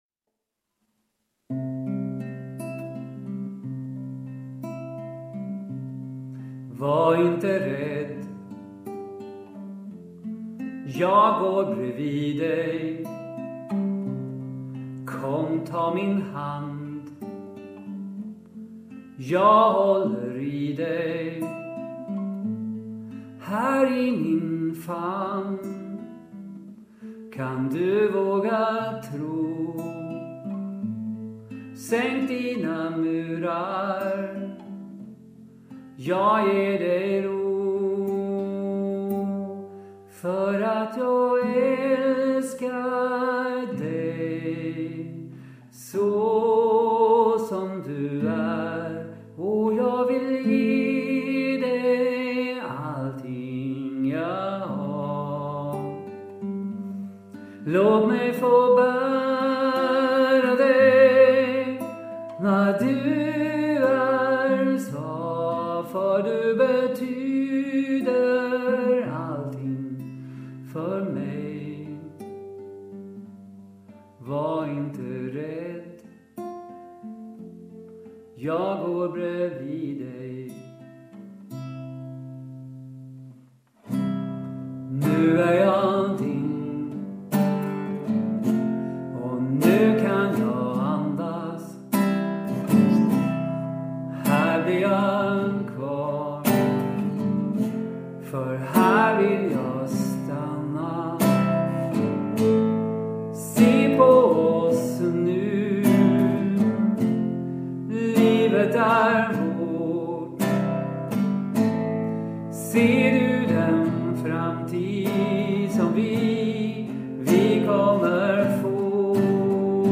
Just denna inspelning är gjord hemma 250131.